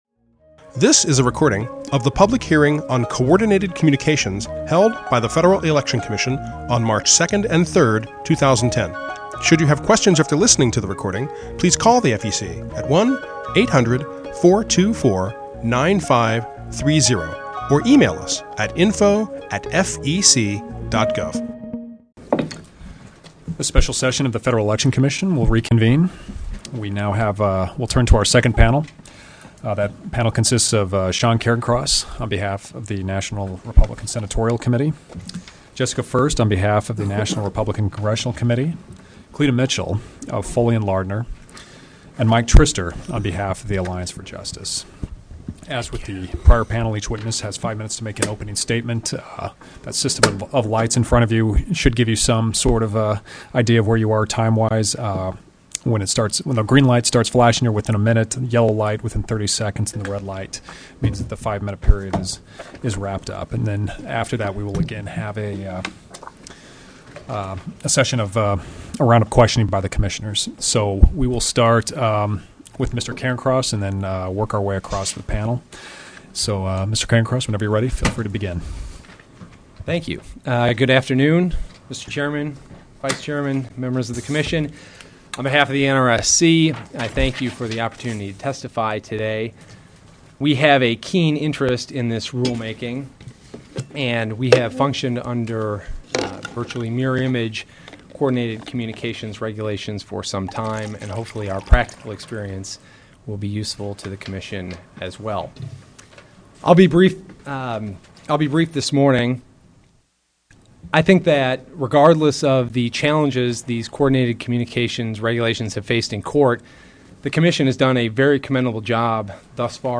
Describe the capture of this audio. March 2-3, 2010 Public Hearing on Coordinated Communications | FEC